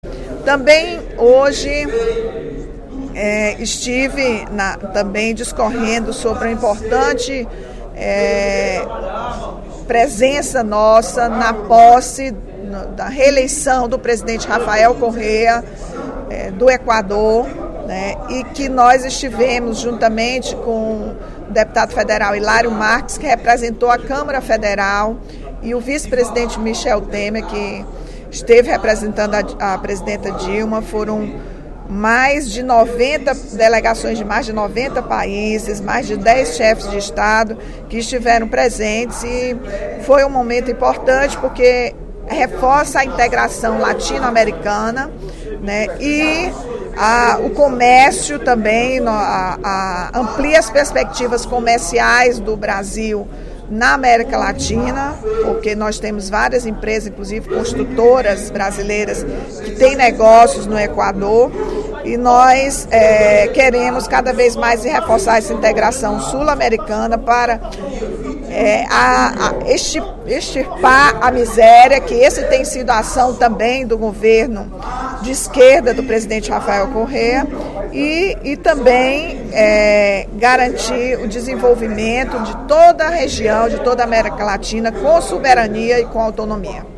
A posse de Rafael Correa, reeleito presidente do Equador, foi tema de pronunciamento da líder do PT, deputada Rachel Marques, no primeiro expediente da sessão plenária desta quarta-feira (29/05) da Assembleia Legislativa.